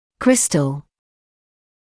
[‘krɪstl][‘кристл]кристалл; кристаллический